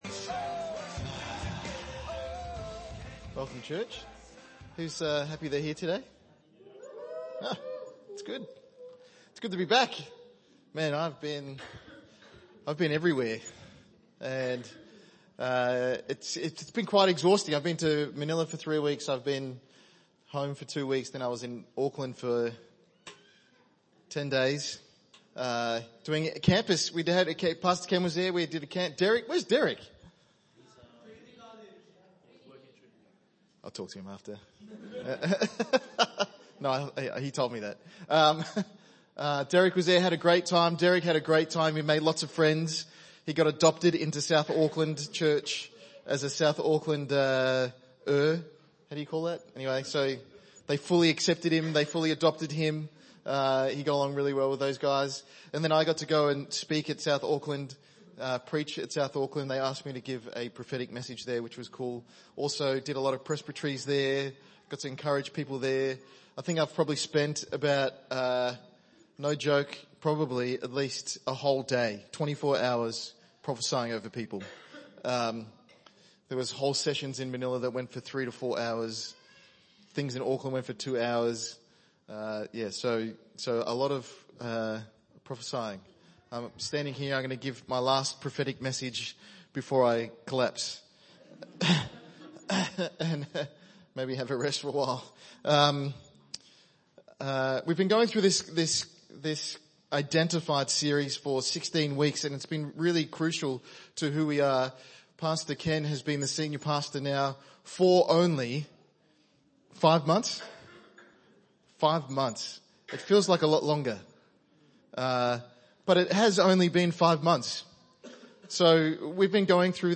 ENM Sermon